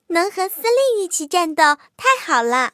野牛II编入语音.OGG